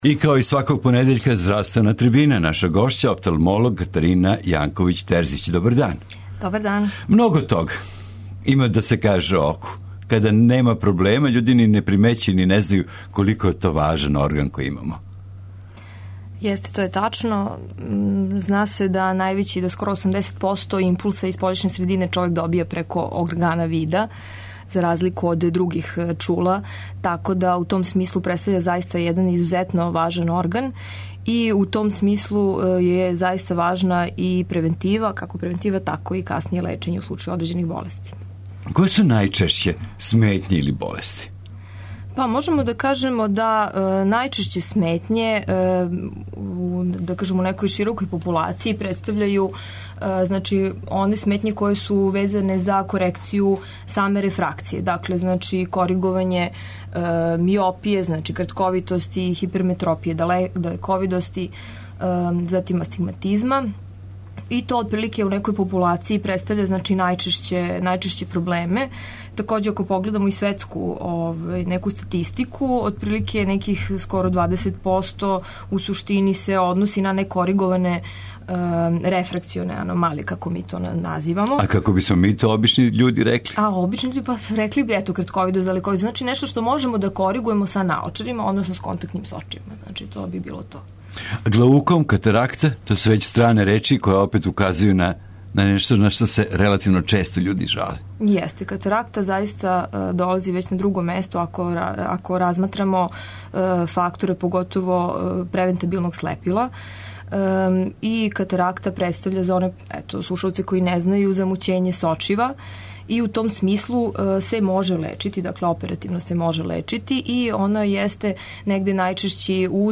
Наша гошћа ће радо одговорити и на Ваша питања уколико током емисије позовете на наше телефоне.